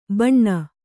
♪ baṇṇa